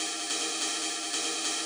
K-6 Ride.wav